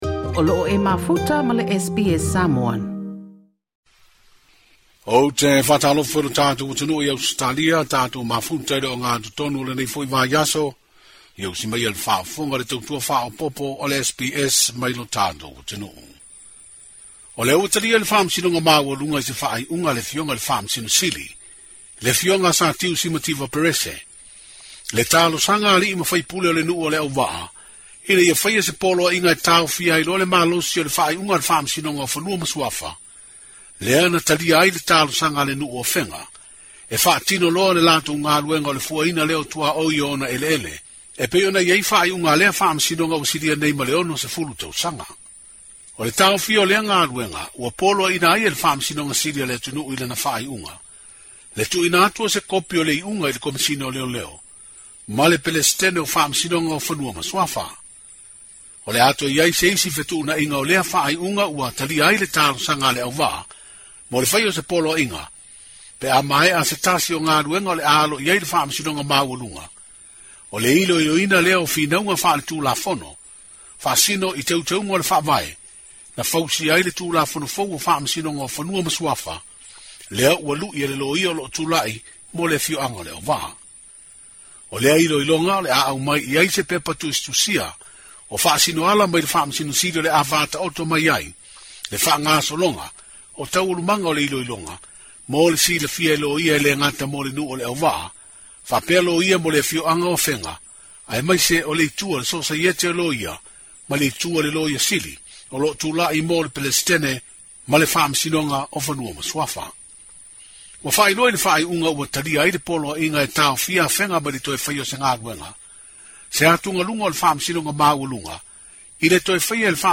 O se vaega lenei o le molimau a le palemia o Samoa, Fiame Naomi Mata'afa, i toe sauniga o le sa avea ma sui faipule o Vaimauga Numera Tasi, Tuisugaletaua Sofara Aveau.